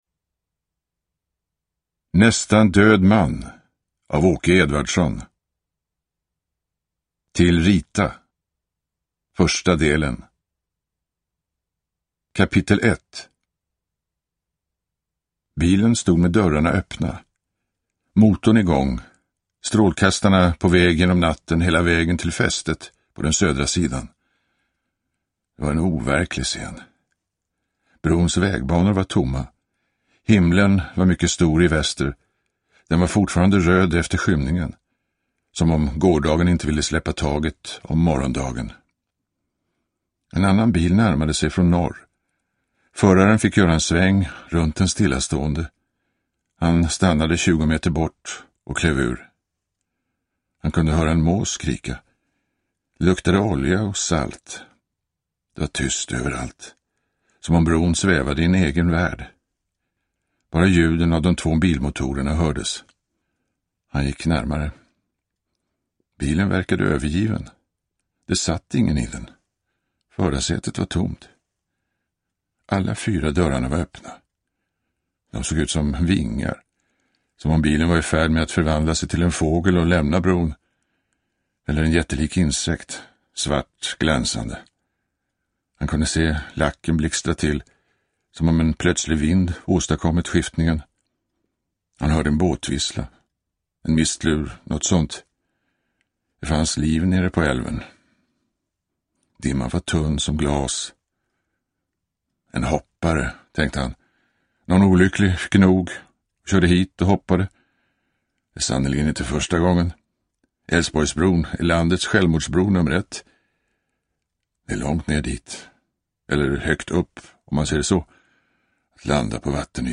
Nästan död man – Ljudbok – Laddas ner
Uppläsare: Torsten Wahlund